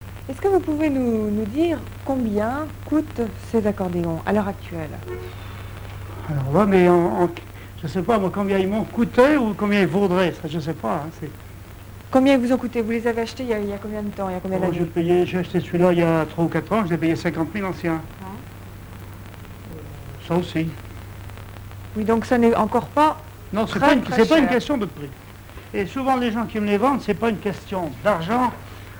accordéon(s), accordéoniste
Musique, bal, émission de radio RCF 85
Catégorie Témoignage